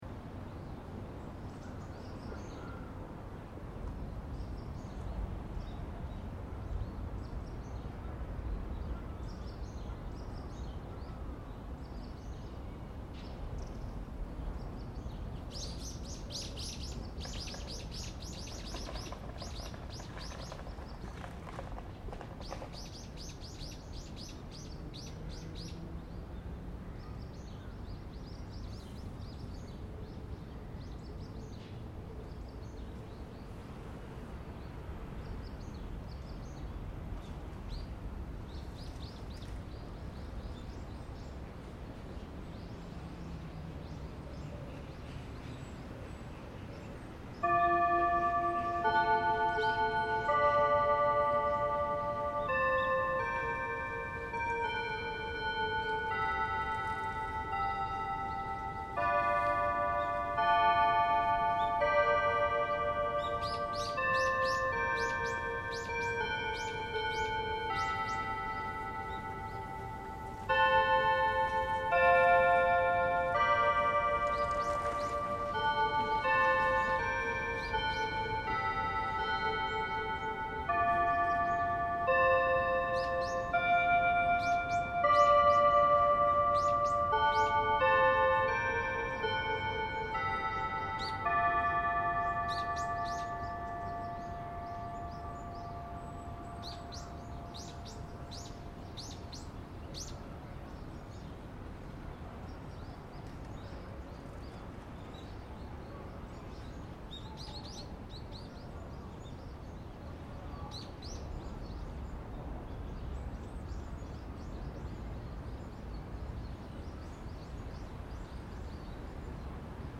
However, just across the river is the Peace Clock Tower which chimes every day at 8:15am - the exact time the first atomic bomb was dropped on a human population and changed the world forever.
The first 45 seconds of the recording captures ambient sounds from the location on December 6, 2024 – birds, the hum of traffic, people passing on their way to work. Then at 8:15am exactly, the chimes of the atomic clock begin – as they have done since 1967 when the clock was constructed.
The remainder of the recording returns to the sounds of modern Hiroshima progressing into the future.